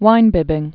(wīnbĭbĭng)